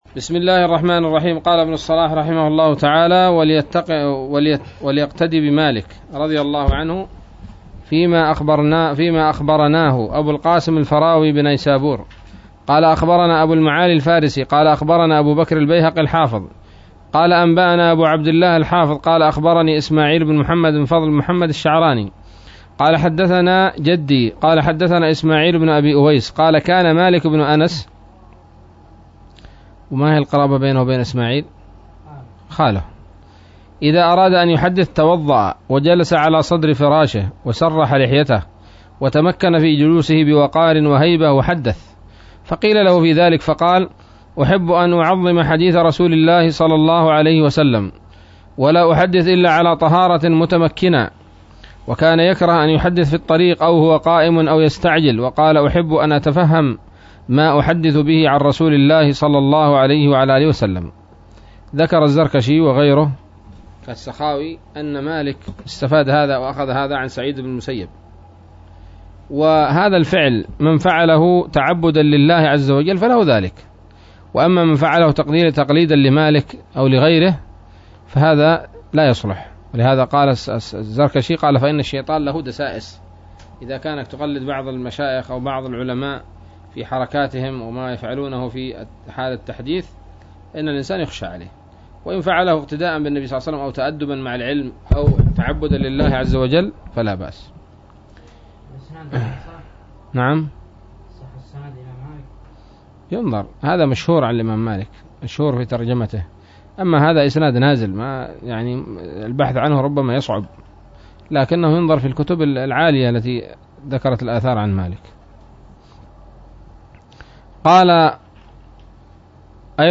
الدرس الخامس والثمانون من مقدمة ابن الصلاح رحمه الله تعالى